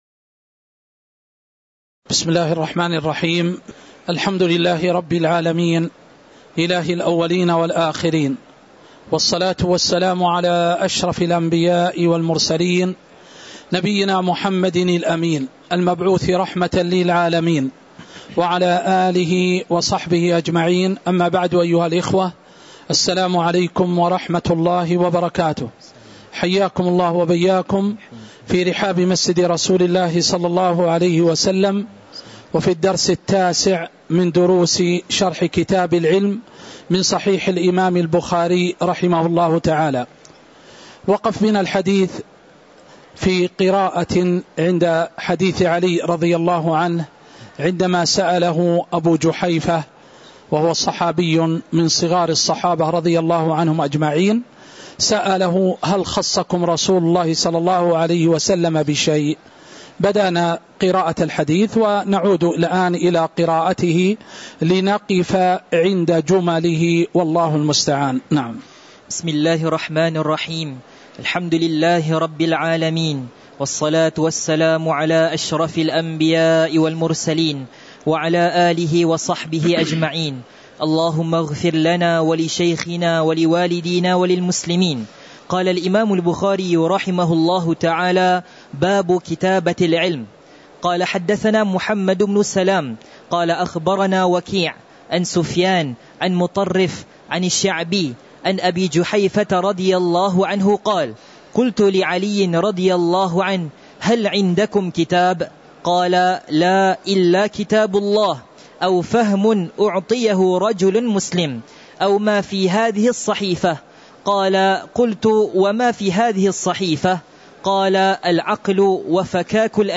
تاريخ النشر ٢٣ محرم ١٤٤٦ هـ المكان: المسجد النبوي الشيخ